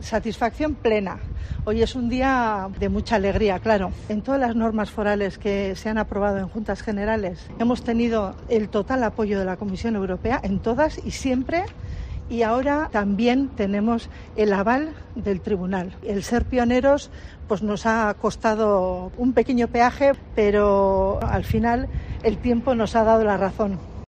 Aintzane Oiarbide, diputada de Infraestructuras Viarias de Gipuzkoa